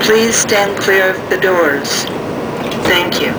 Almost Every 7k Announcement
The ones marked "CAF" are the ones that were initially rolled out on the CAF 5000-Series railcars, and then they were used on the Breda 2000 and 3000-Series railcars following their rehabilitation in the mid 2000s.